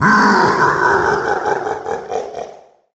Sound effect from Super Mario Galaxy
SMG_Bowser_Laugh.oga.mp3